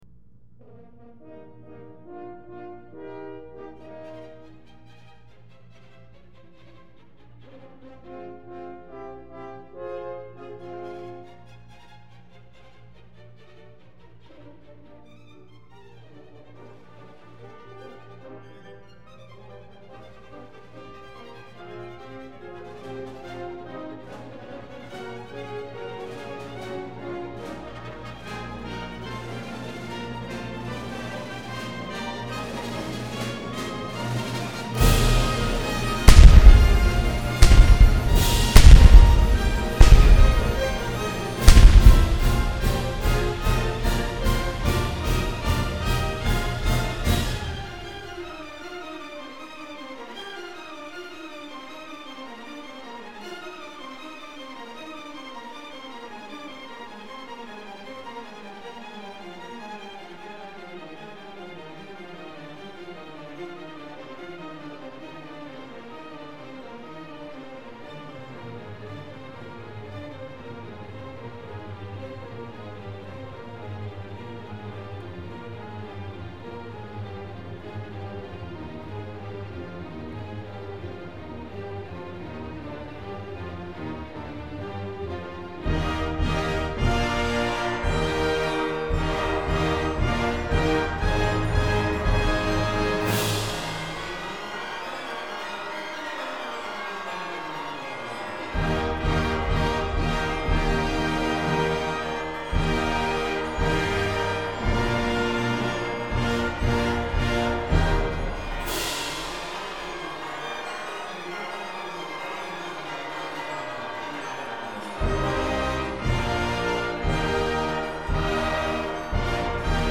本片錄音工程艱鉅，是對錄音技術的革命性考驗。
***精彩片段(加農砲 ***請將音響音量調小 以免震破喇叭哦 )